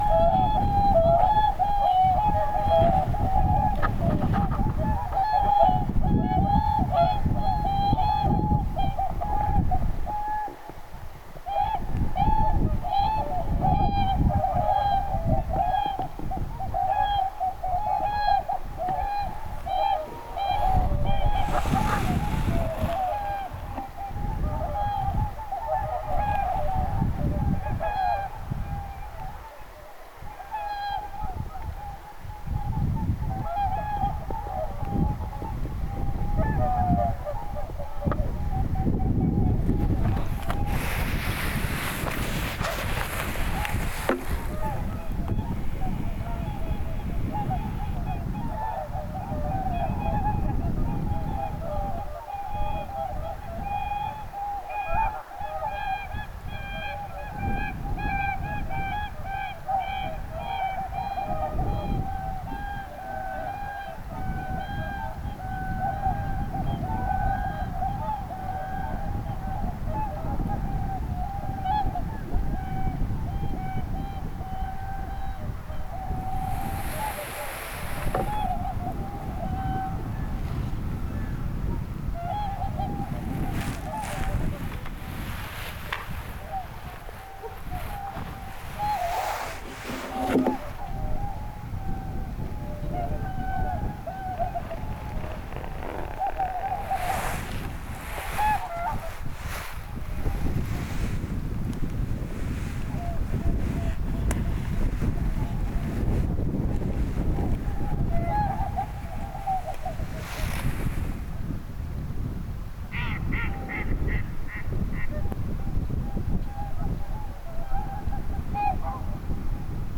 laulujoutsenet huutavat rannalla
laulujoutsenet_huutavat_rannalla.mp3